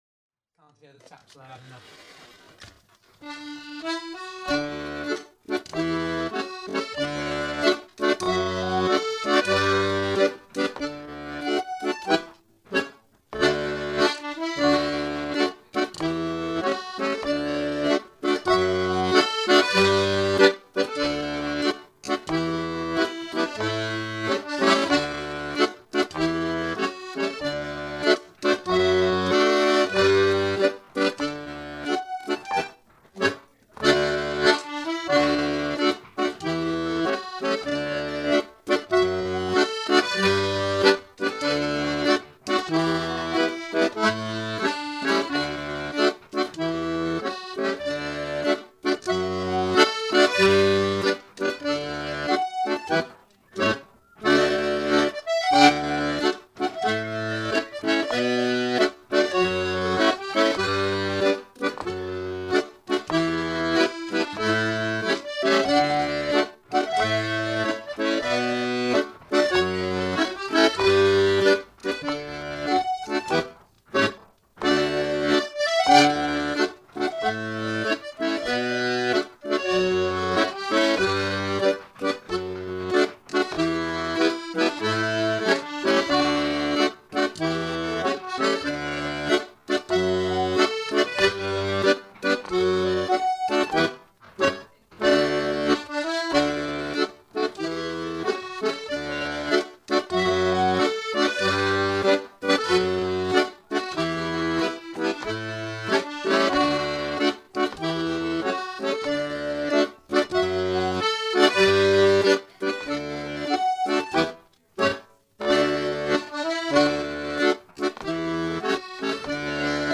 Clog Music 15% slower